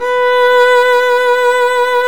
Index of /90_sSampleCDs/Roland L-CD702/VOL-1/STR_Violin 1-3vb/STR_Vln1 % marc